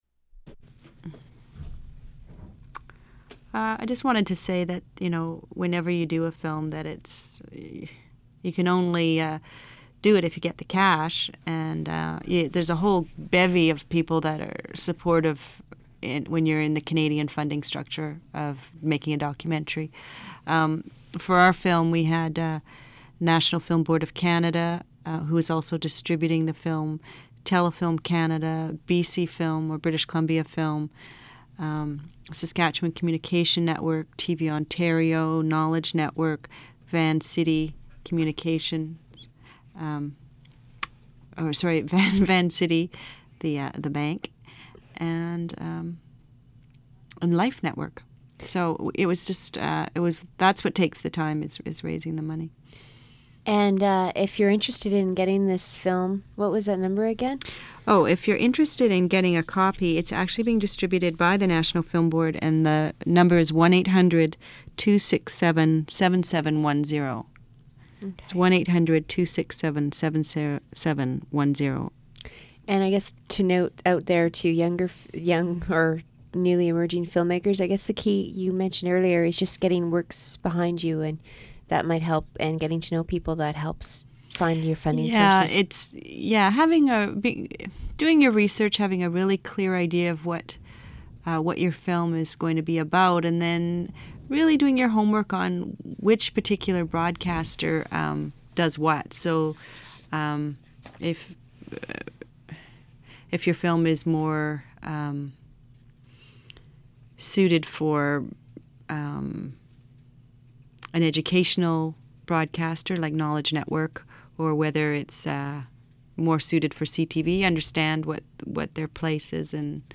Women interview for the Morning Show